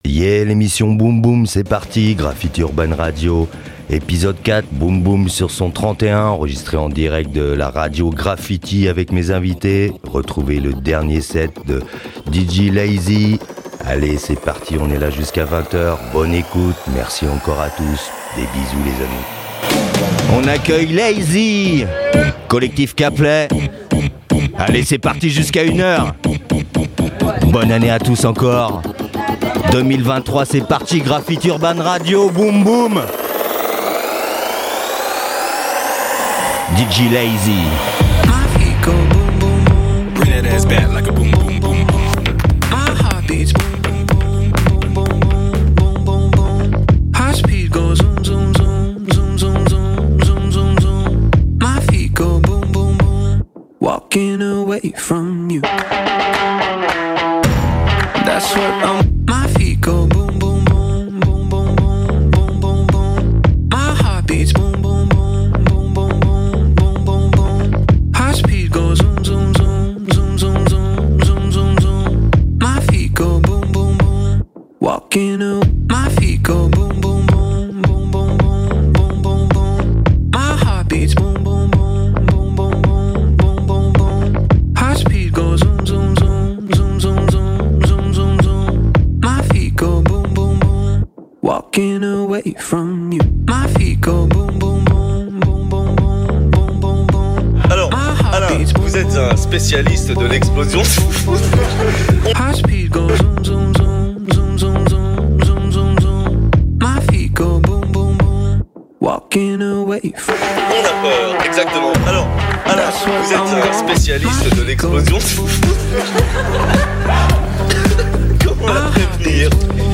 l'émission Bass Music, Trap, Electro
Des Dj's, des Mix pour l'apèro.